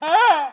ANIMAL_Goose_mono.wav